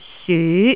這些詞語的韻母都是〔i〕。